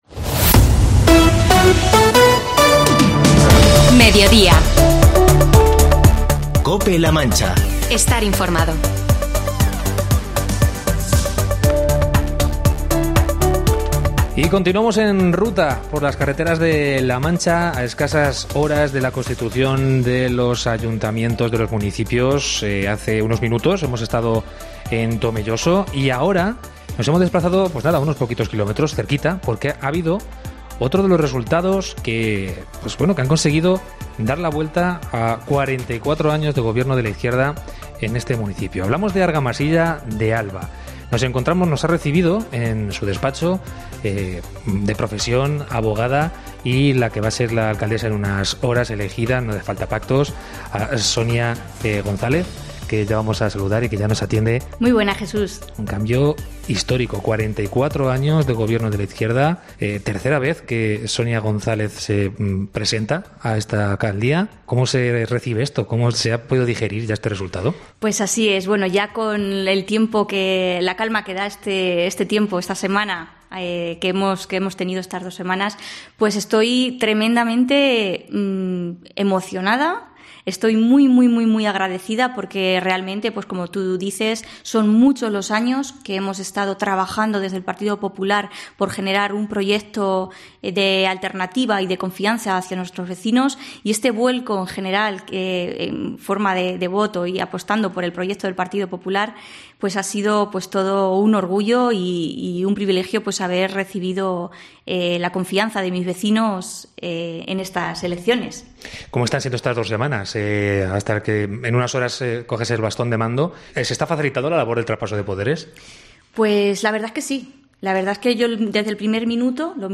Entrevista con Sonia González, próxima alcaldesa de Argamasilla de Alba